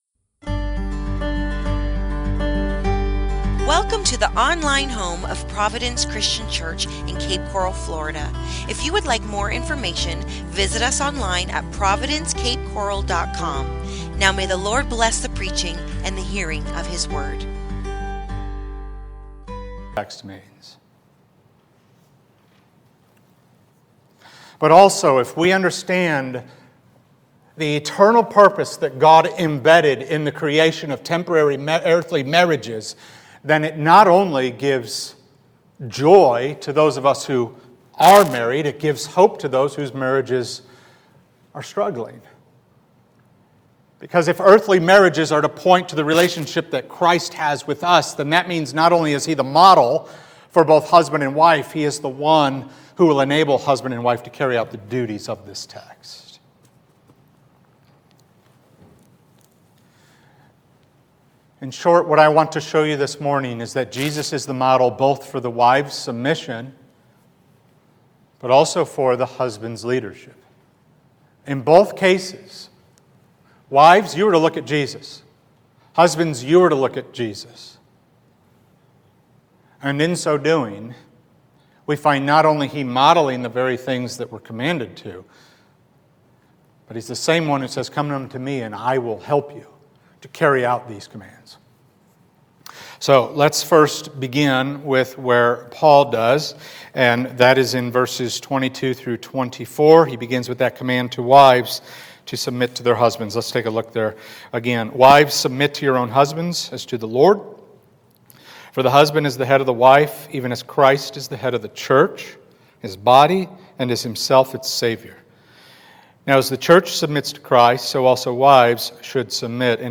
Marriage As a Model | SermonAudio Broadcaster is Live View the Live Stream Share this sermon Disabled by adblocker Copy URL Copied!